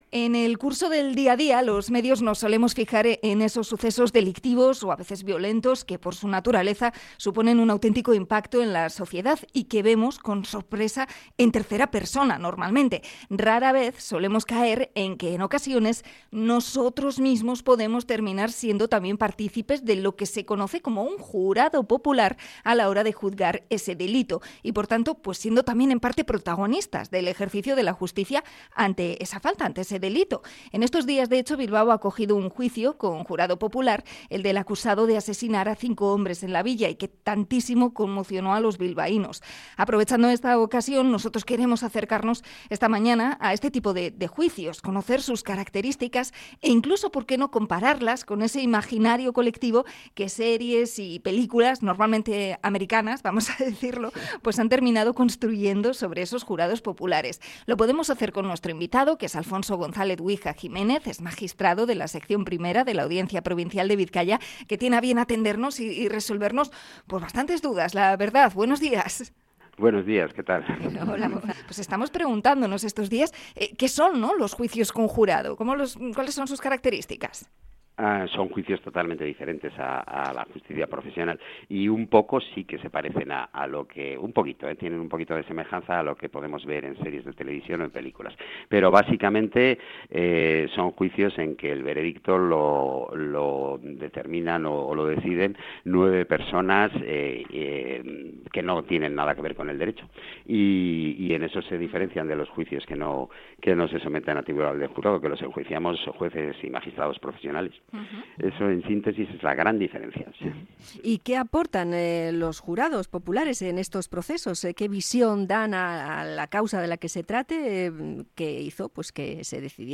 El juez Alfonso González‑Guija nos cuenta cómo funciona este mecanismo judicial que tanto hemos visto en la televisión